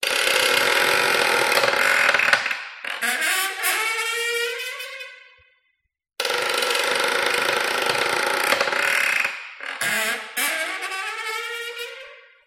Дверь скрипит и качается от сквозняка